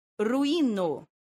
Výslovnost a pravopis
Po kliknutí na příslušné slovo uslyšíte správnou výslovnost, kterou nahlas opakujte.